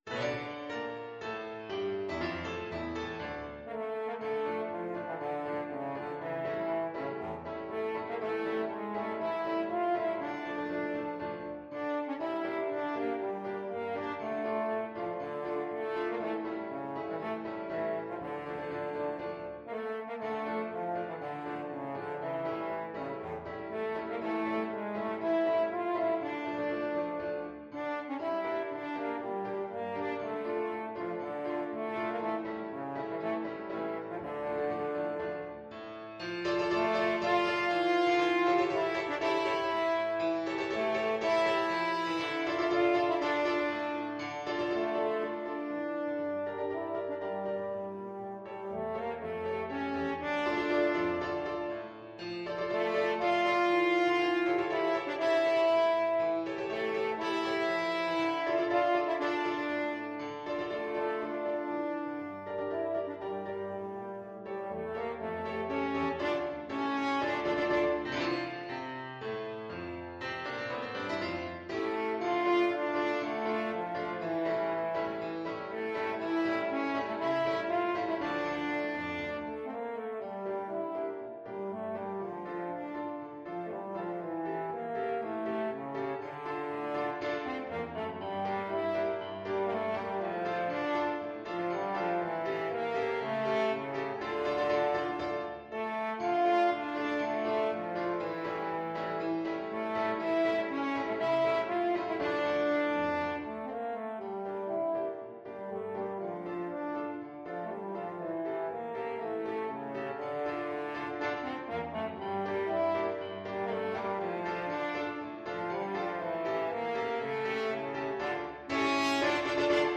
Classical Agapkin, Vasily Farewell of Slavianka French Horn version
French Horn
D minor (Sounding Pitch) A minor (French Horn in F) (View more D minor Music for French Horn )
Allegro = c.120 (View more music marked Allegro)
Classical (View more Classical French Horn Music)